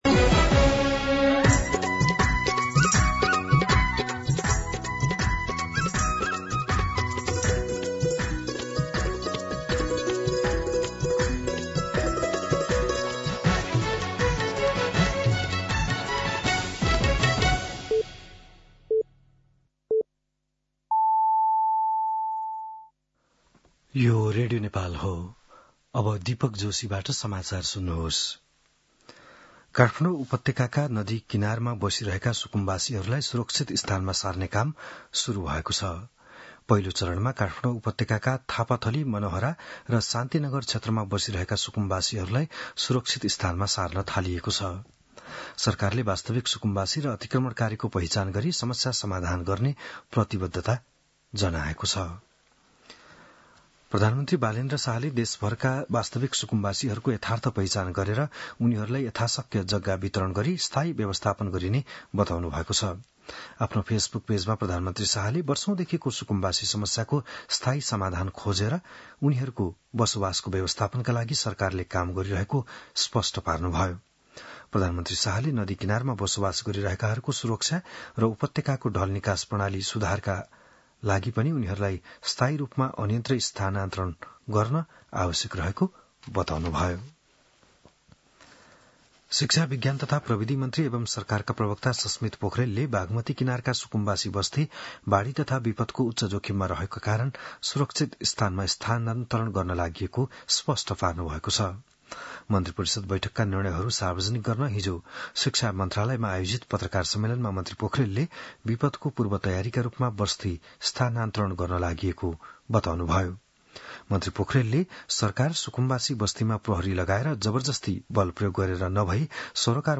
बिहान ११ बजेको नेपाली समाचार : १२ वैशाख , २०८३